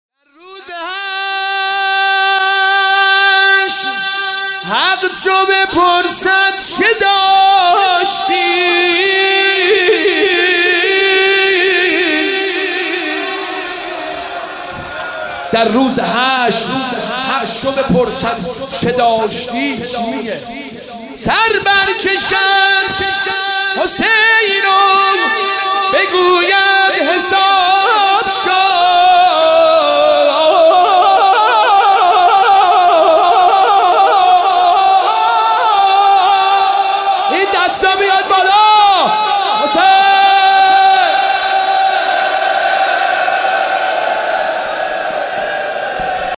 شب سوم محرم
نوحه